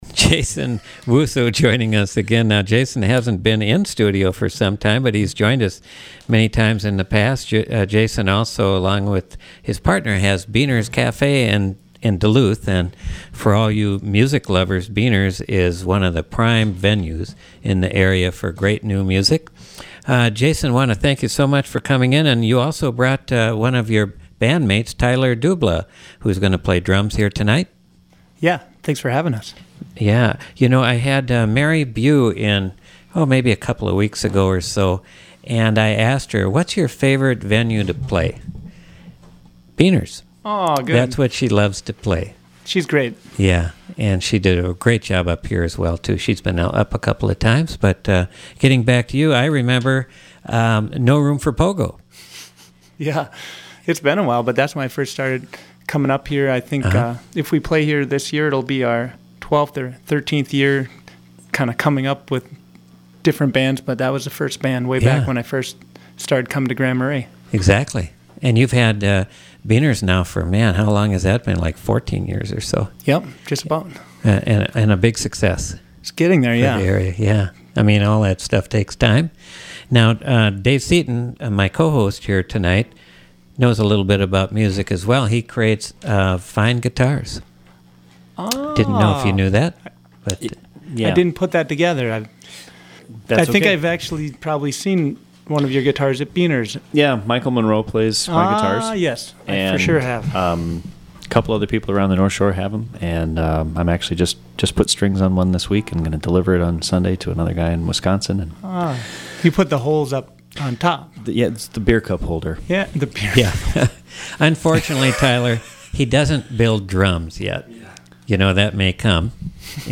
guitar
drums
Live Music Archive